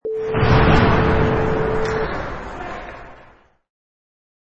Sound files: Hockey Match 3
Various sounds of a hockey match
Product Info: 48k 24bit Stereo
Category: Sports / Hockey
Try preview above (pink tone added for copyright).
Tags: iceskate
Hockey_Match_3.mp3